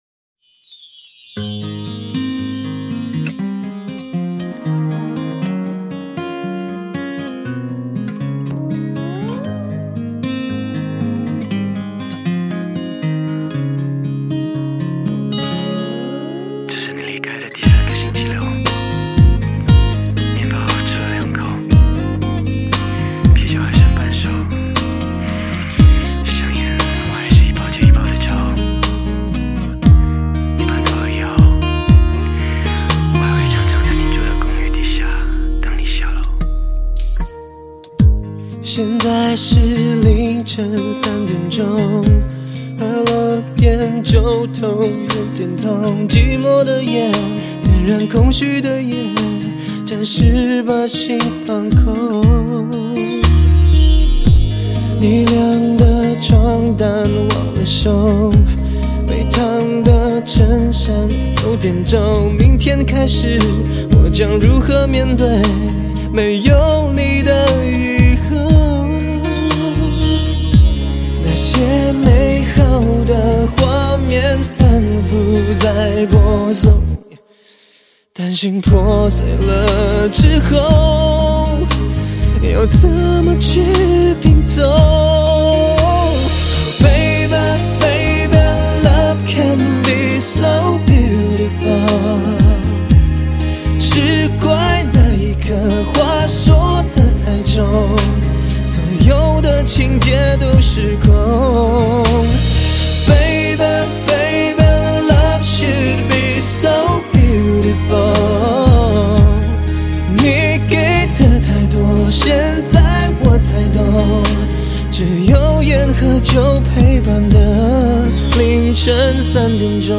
R&B陪伴你的寂寞
“陪伴系情歌”陪伴系情歌